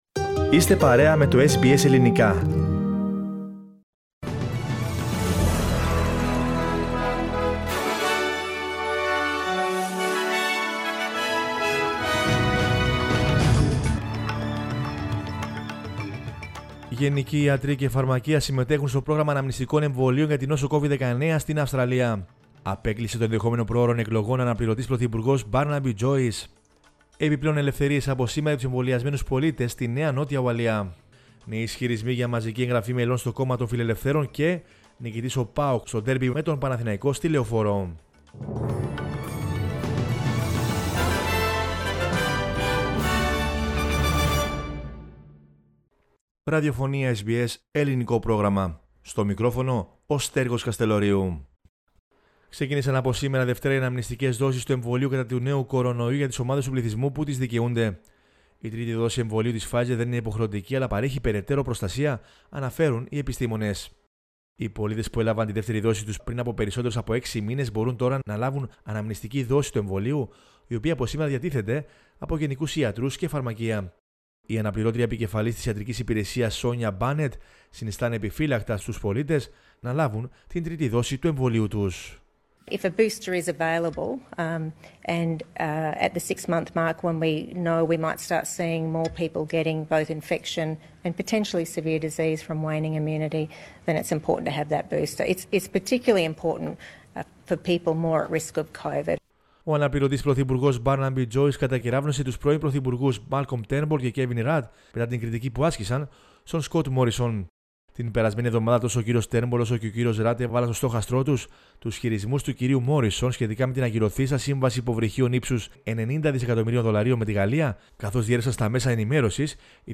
Δελτίο Ειδήσεων 08.11.21